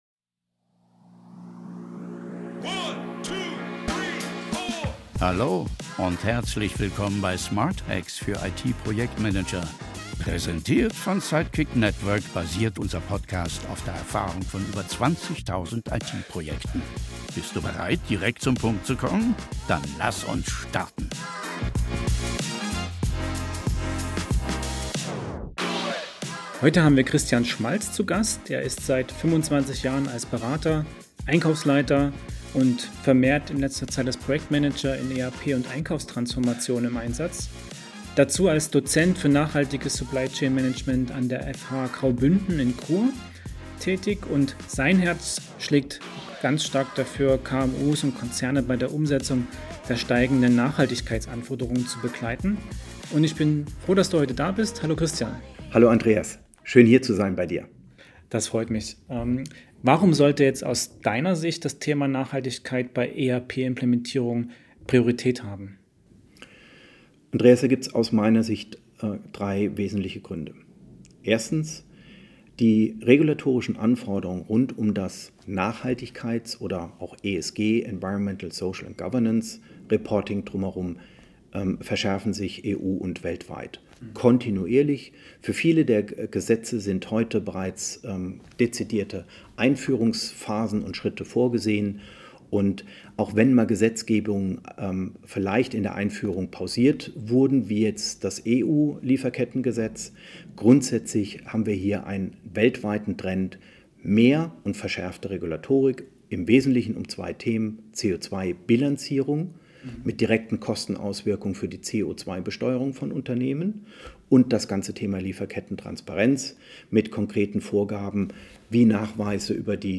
Im Gespräch werden Herausforderungen und Chancen einer nachhaltigen ERP-Transformation diskutiert sowie praxisnahe Ansätze und Maßnahmen vorgestellt. Ziel ist es, Unternehmen dabei zu unterstützen, nachhaltige Strategien nicht nur als Pflicht, sondern als Wettbewerbsvorteil zu begreifen.